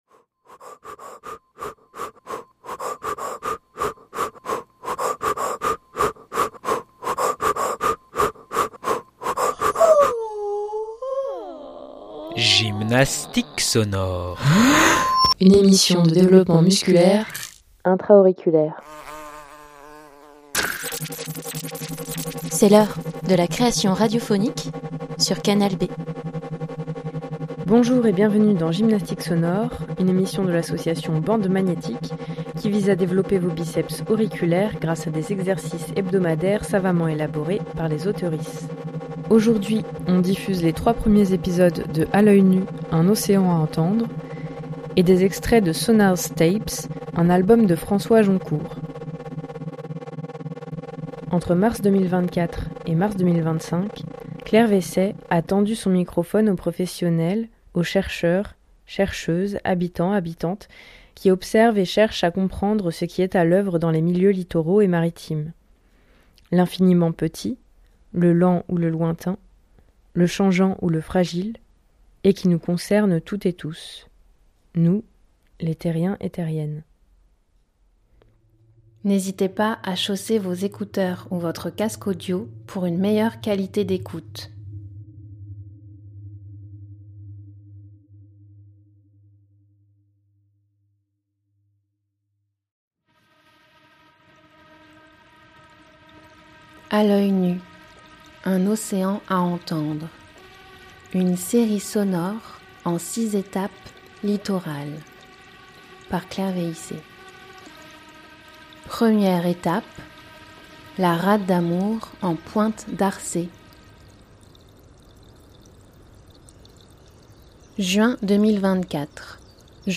Série sonore en 6 étapes littorales du parc naturel marin de l'estuaire de la Gironde et de la mer des Pertuis